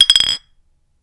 Glass Bottle Clink
bottle clink ding glass rattle sound effect free sound royalty free Sound Effects